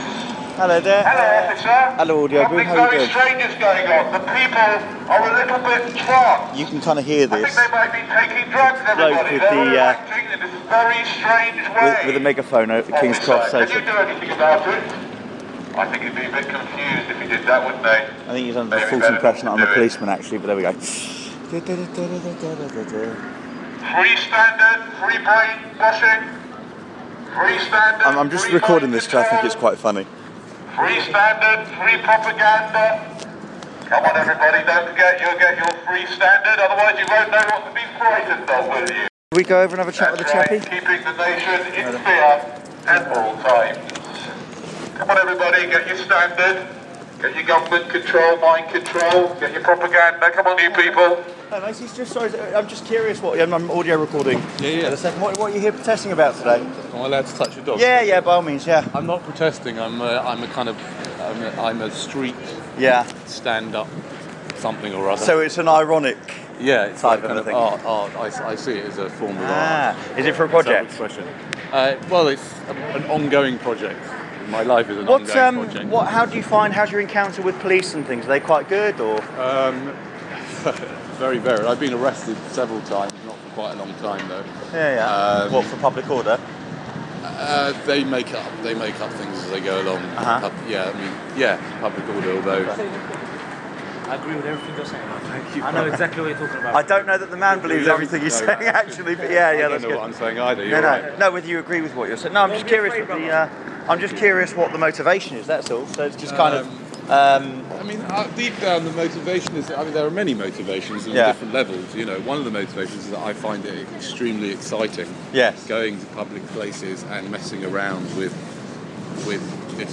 Audio my encounter & interview with a Megaphone protester